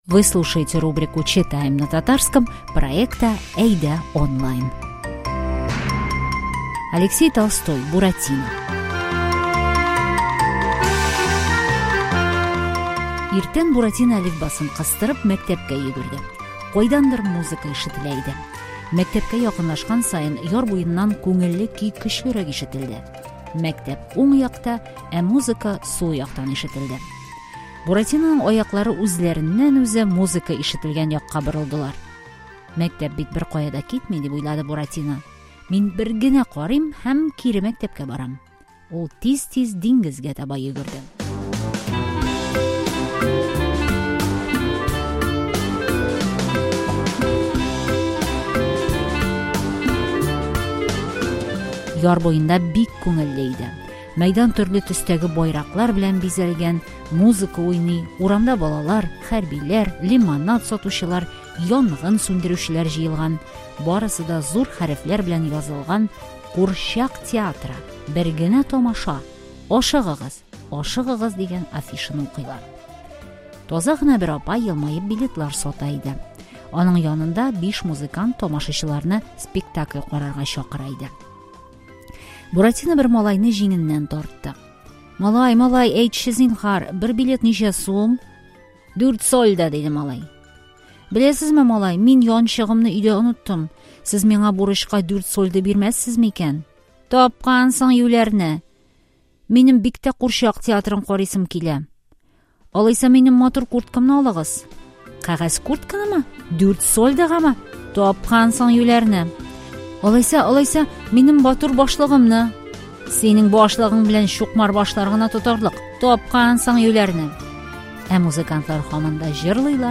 Сегодня читаем всем хорошо известную сказку – приключения про Буратино и золотой ключик! Текст адаптирован для изучающих язык, мы прилагаем красивую аудиосказку, перевод ключевых фраз и тест по содержанию и лексике.